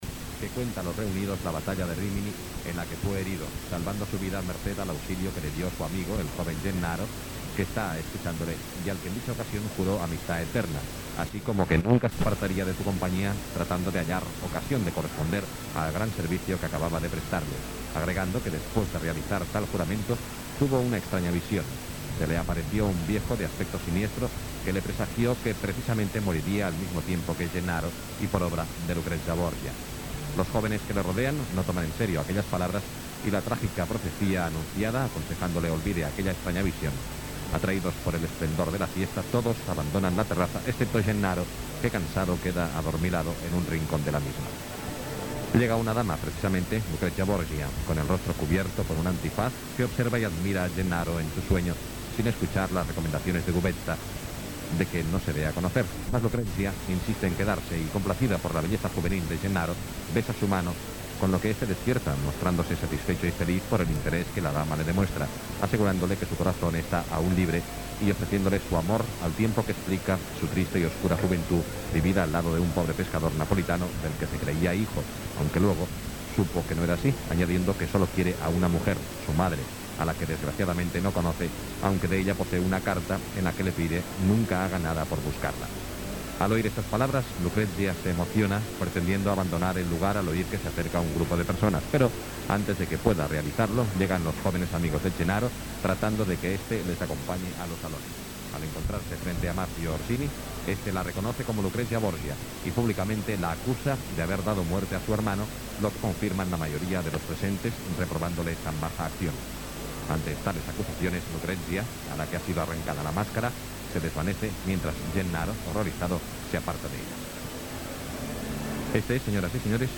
Transmissió des del Gran Teatre del Liceu de Barcelona.
Musical